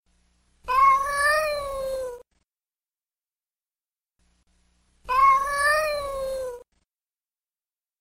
Katt Meowing v7